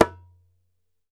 ASHIKO 4 0IL.wav